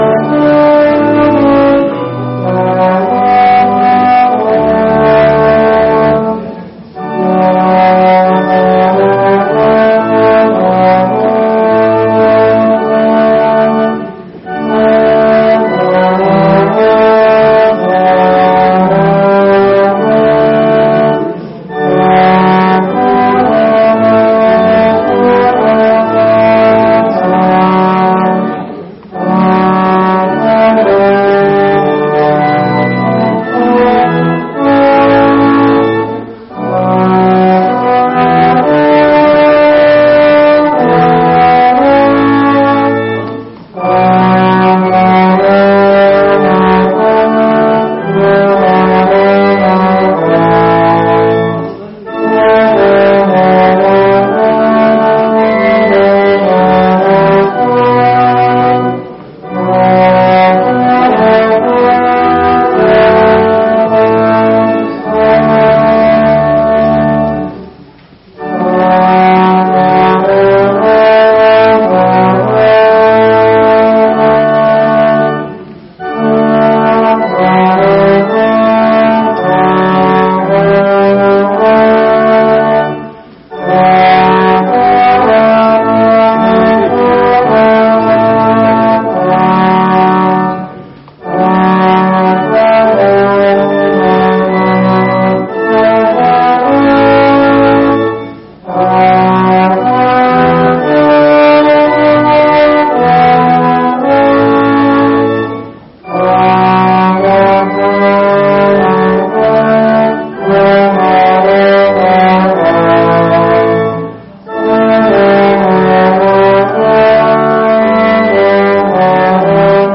Gottesdienst Sonntag 26.10.2025 | Evangelisch-altreformierte Kirchengemeinde Laar
Wir laden ein, folgende Lieder aus dem Evangelischen Gesangbuch und dem Liederheft mitzusingen: Lied 177, 2, Psalm 100, 1 + 2 + 4, Lied 511, 1 – 3, Lied 408, 1 – 3, Psalm 34, 1 + 2 + 4, Lied 410, 1 + 2 + 4
Andacht Sonntag 26.10.2025 als Audiodatei zum Download